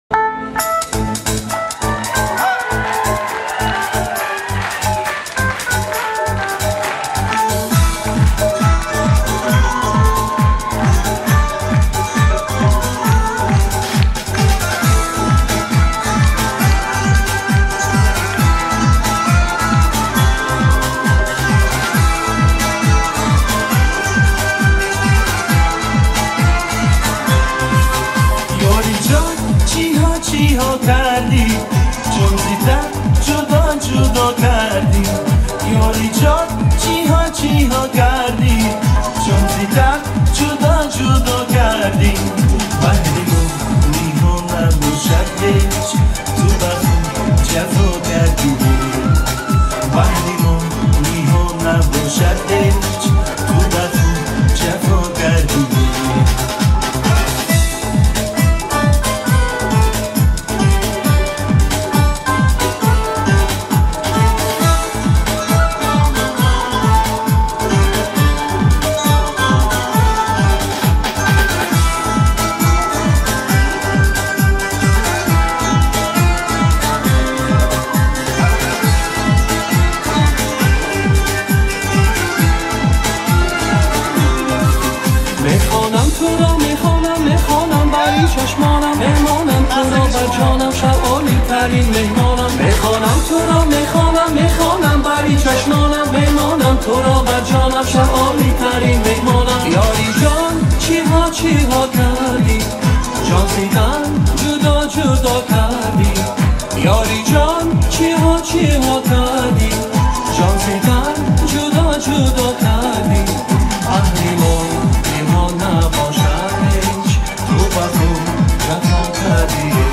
• Категория: Таджикские песни